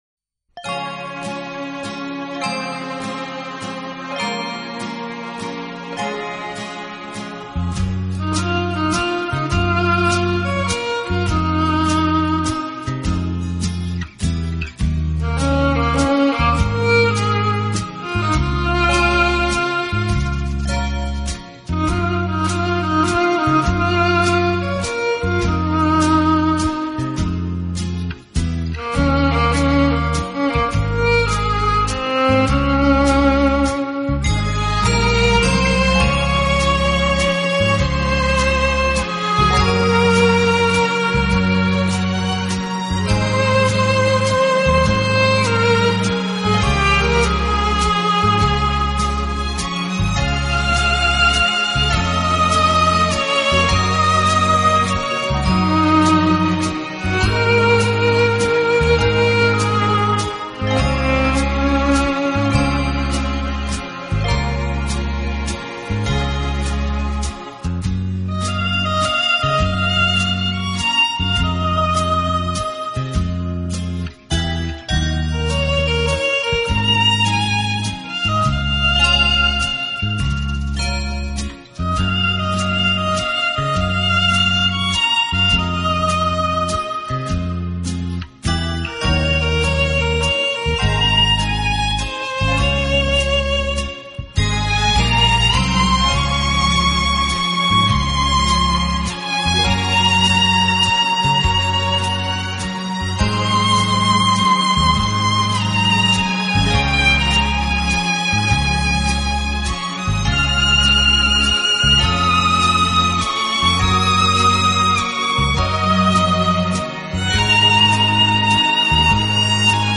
音乐类型：Instrumental